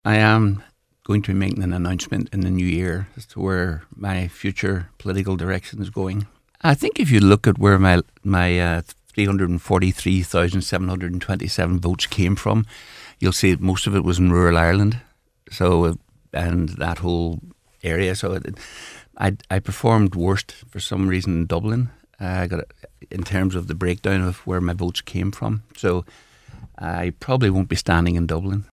Speaking to Newstalk this week, the former Dragon’s Den star confirmed that he will be seeking to secure a career in politics in the near future and represent the people of rural Ireland: